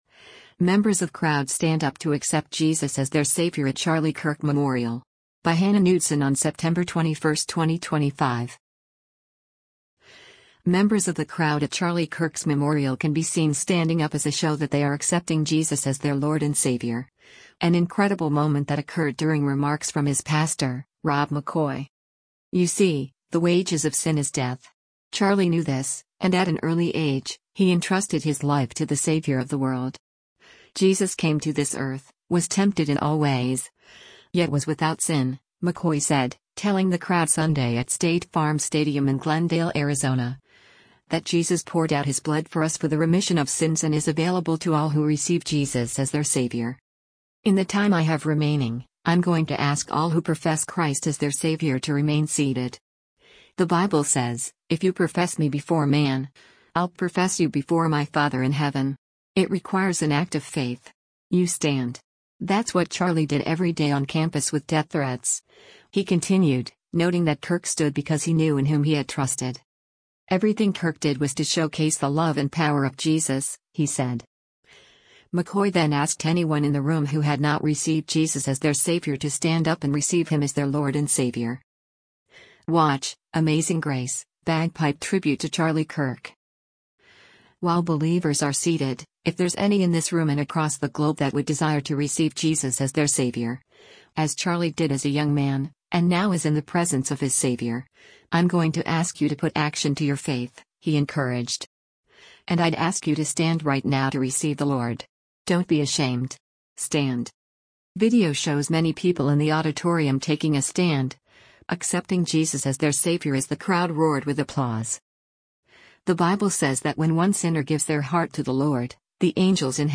Video shows many people in the auditorium taking a stand, accepting Jesus as their Savior as the crowd roared with applause.